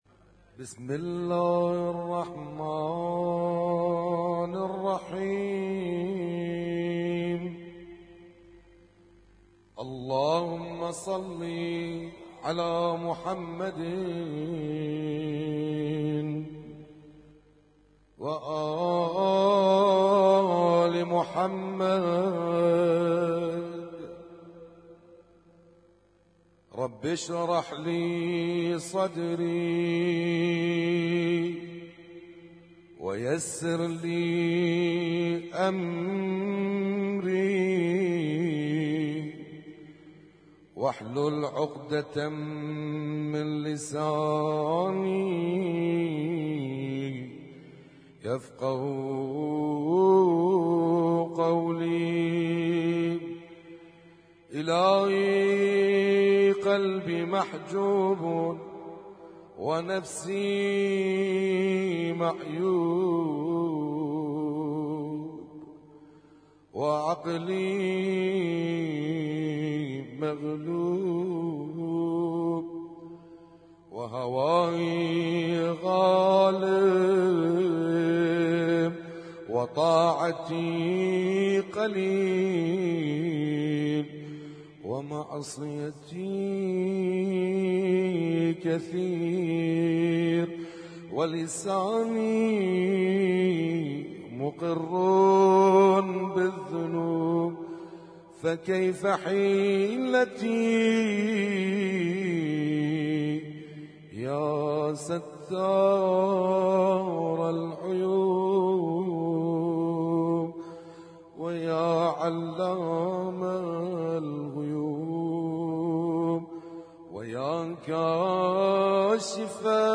Husainyt Alnoor Rumaithiya Kuwait
اسم التصنيف: المـكتبة الصــوتيه >> الادعية >> دعاء كميل